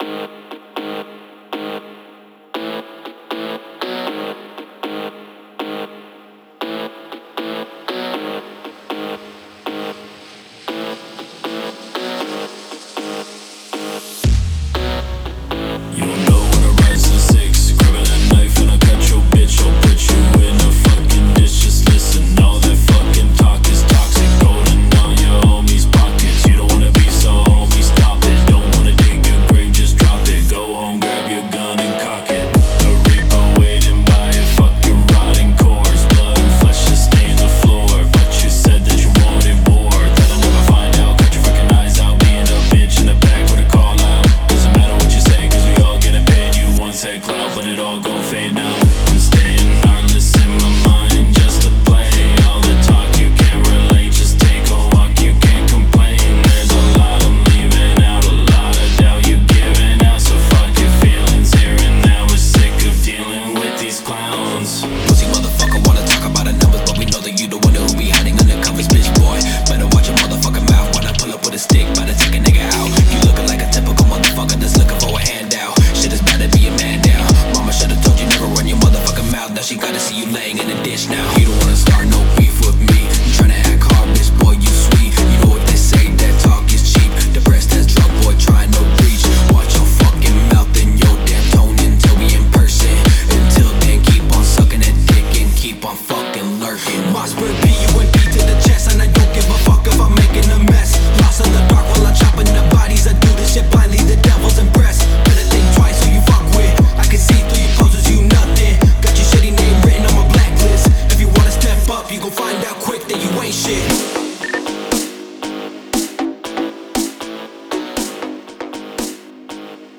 басы в авто